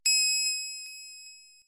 MiningPing.mp3